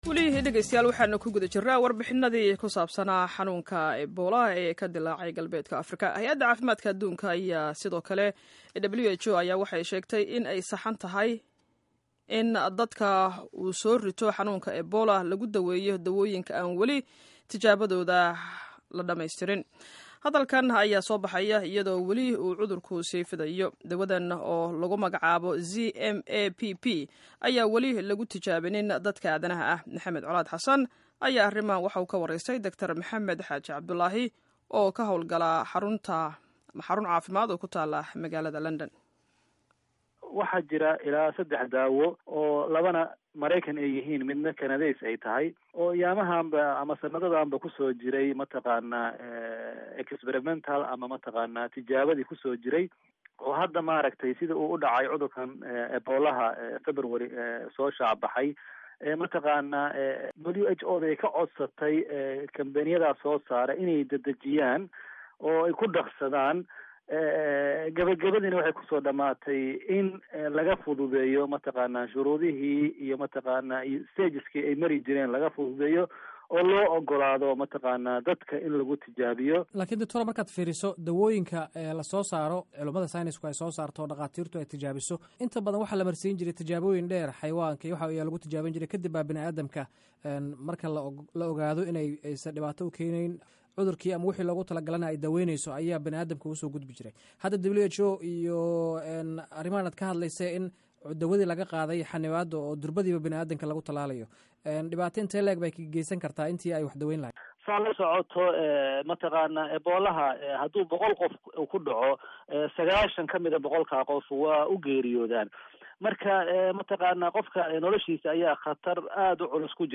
wareysiga Ebola